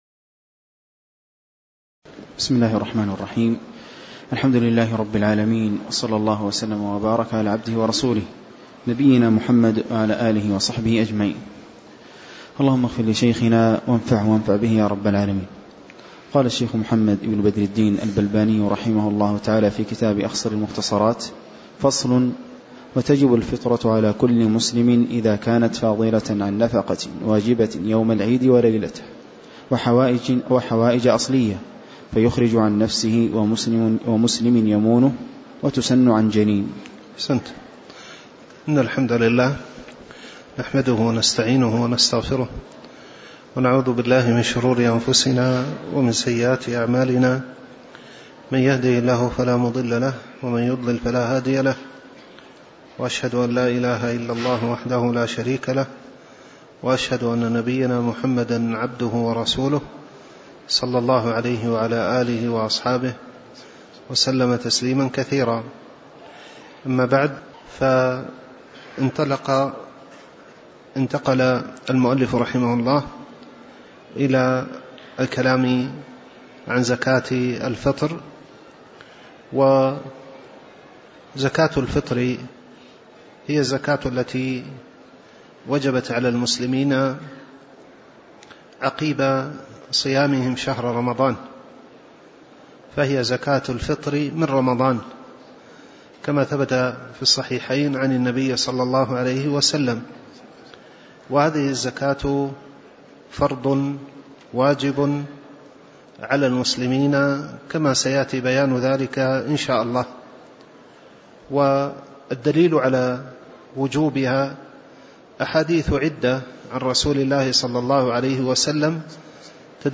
تاريخ النشر ١٥ محرم ١٤٤٠ هـ المكان: المسجد النبوي الشيخ